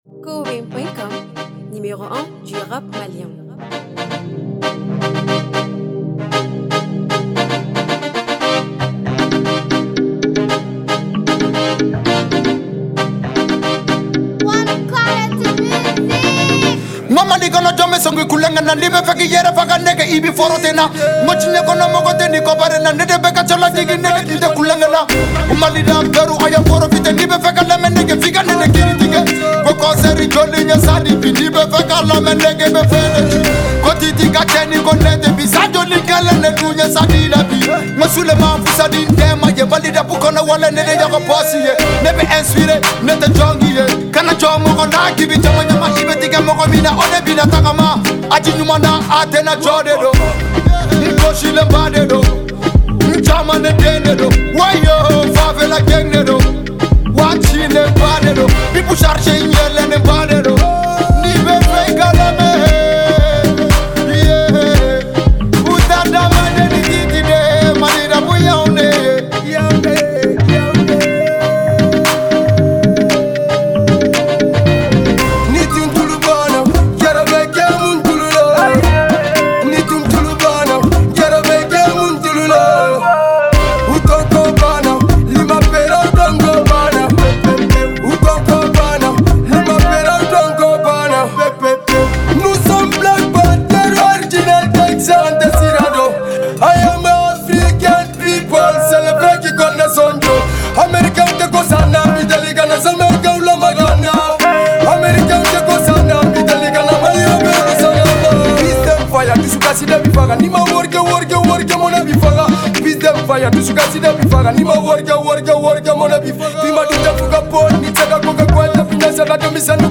musique Mali afro-rap.